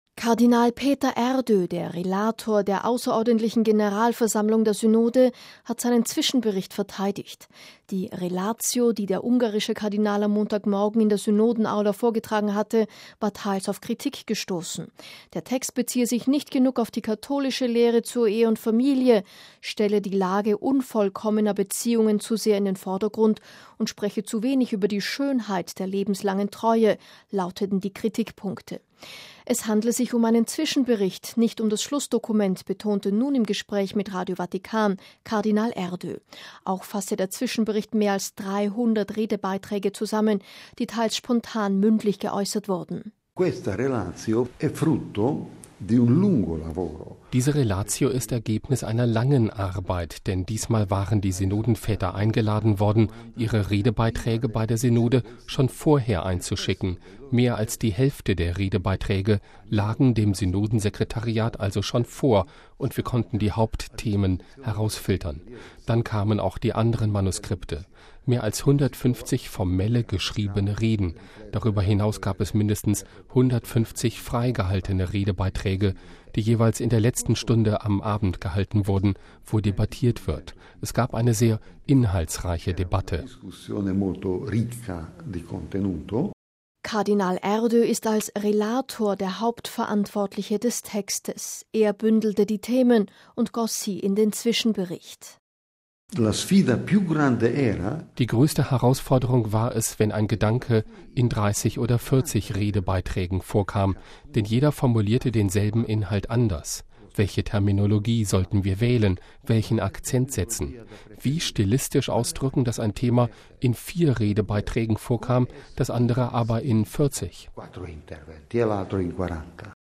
Es handle sich um einen Zwischenbericht, nicht um das Schlussdokument, betonte nun im Gespräch mit Radio Vatikan Kardinal Erdö.